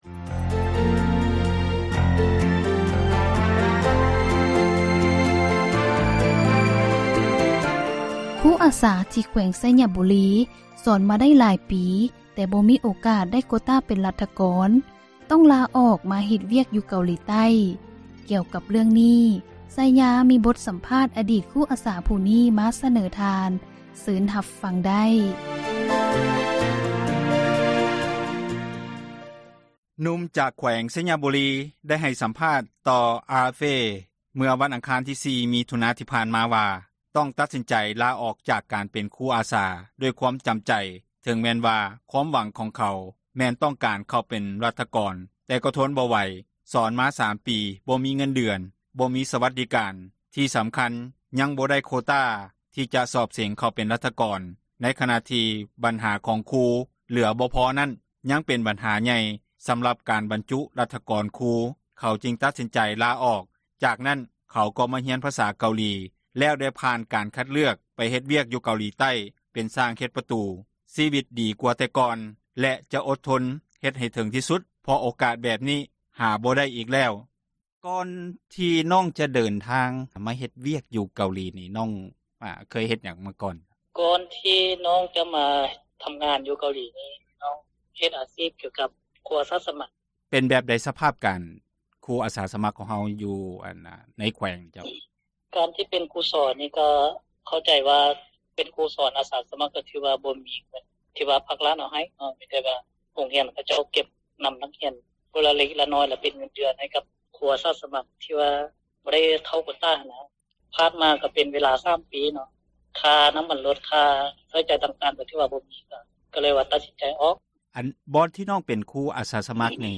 ຈາກນັ້ນ ເຂົາກໍມາຮຽນພາສາເກົາຫຼີ ແລ້ວໄດ້ຜ່ານການຄັດເລືອກໄປເຮັດວຽກ ຢູ່ເກົາຫຼີໃຕ້ເປັນຊ່າງເຮັດປະຕູ. ເມື່ອຖາມວ່າ ວຽກໃໝ່ໄດ້ເງິນ ເດືອນພໍກິນບໍ່ ລາວຕອບວ່າ ພໍຢູ່ພໍກິນ ຊີວິດດີກວ່າແຕ່ກ່ອນ ແລະ ຈະອົດທົນເຮັດໃຫ້ເຖິງທີ່ສຸດ ເພາະໂອກາດ ແບບນີ້ ຫາບໍ່ໄດ້ອີກແລ້ວ. ເຊີນທ່ານຮັບຟັງ ການສຳພາດ.